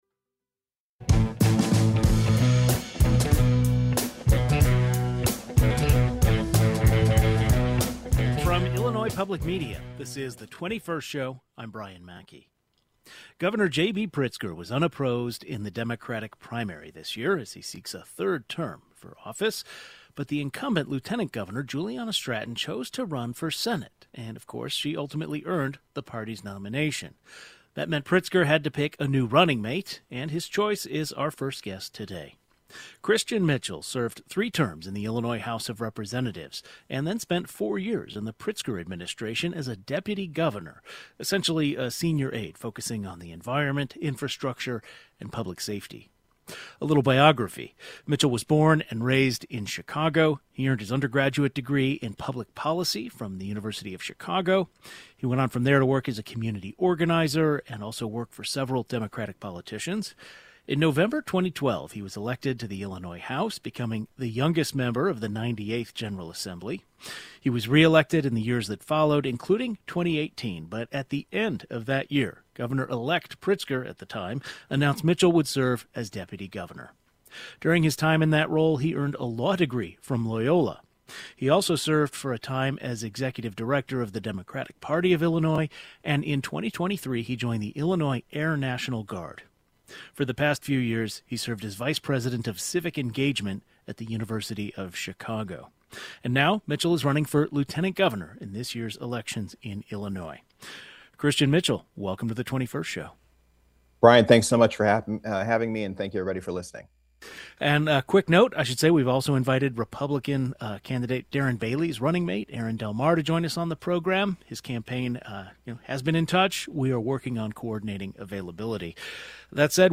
Meet Democratic candidate for Lieutenant Governor Christian Mitchell